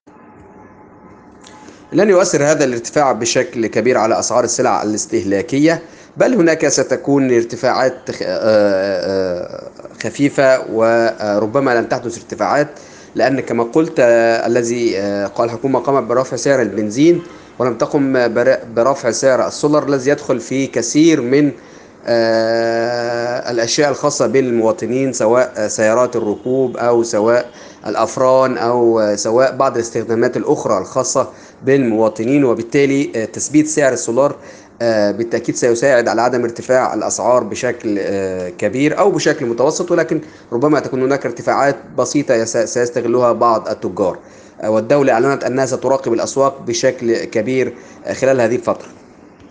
حوار